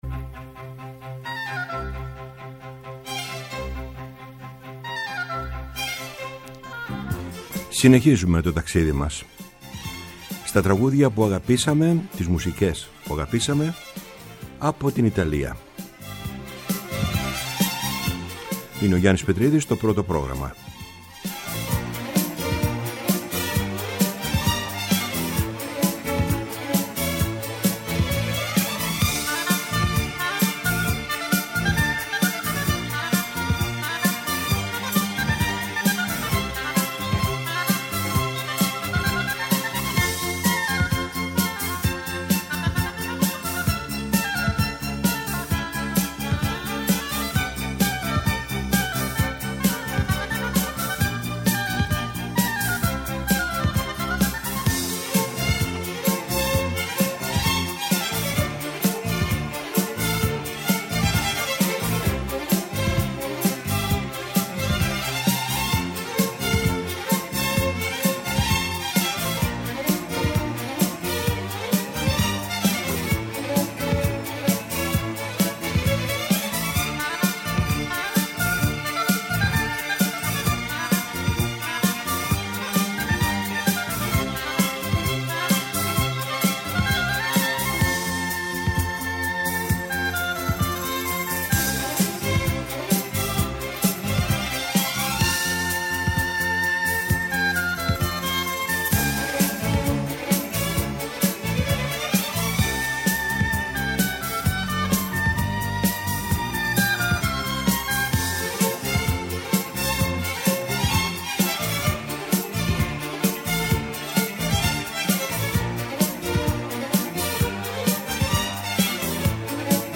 Ιταλικά τραγούδια
Η μακροβιότερη εκπομπή στο Ελληνικό Ραδιόφωνο!